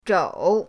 zhou3.mp3